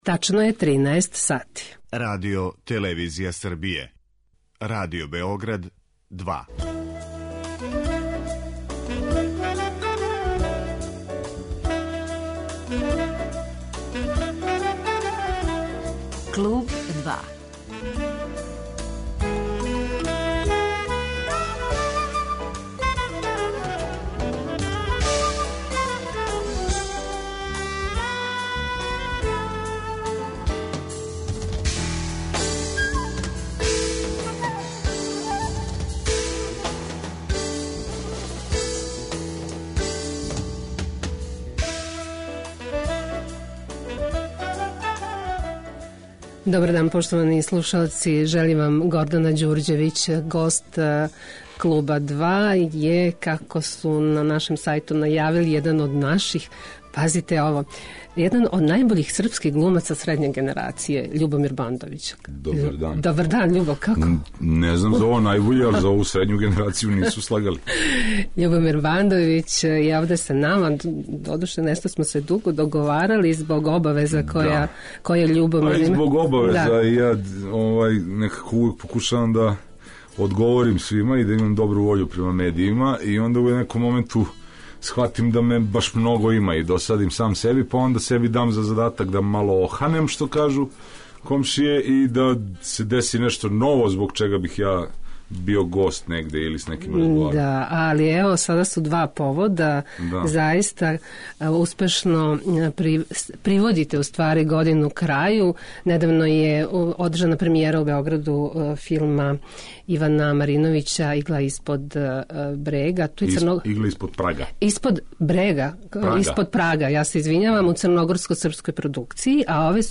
Гост 'Клуба 2' је један од најбољих српских глумаца средње генерације - Љубомир Бандовић, који врло успешно приводи крају 2016. годину.